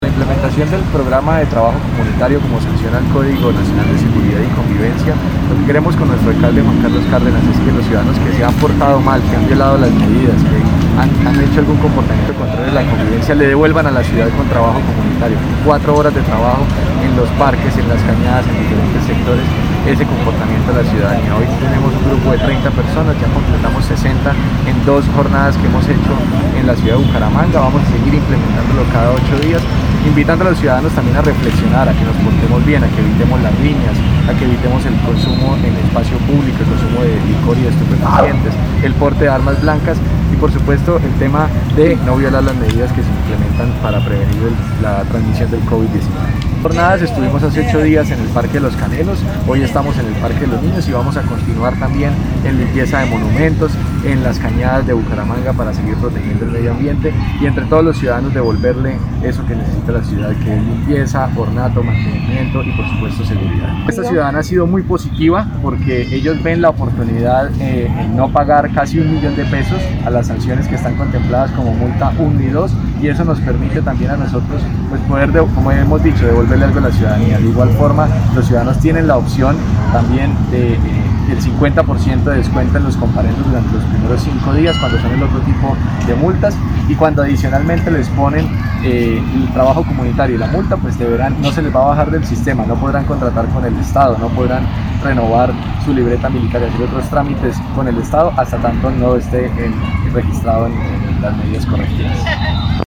José David Cavanzo, secretario del Interior de Bucaramanga